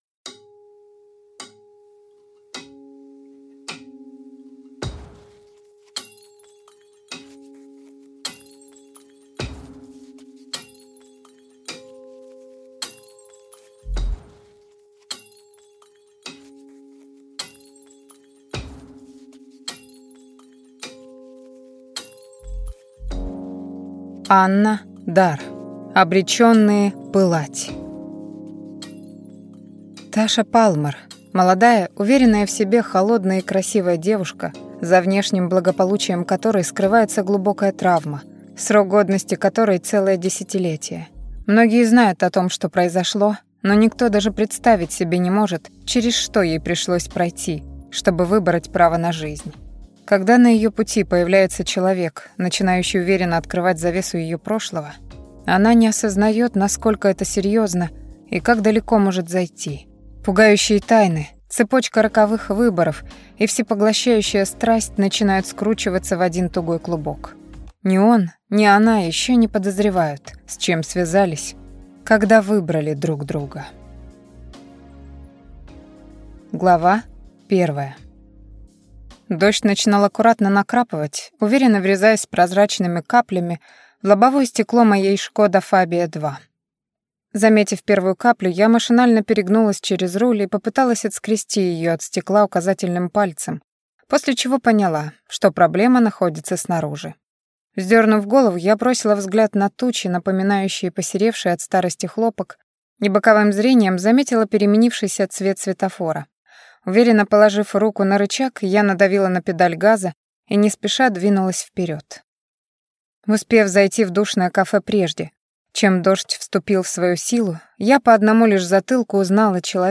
Аудиокнига Обреченные пылать - купить, скачать и слушать онлайн | КнигоПоиск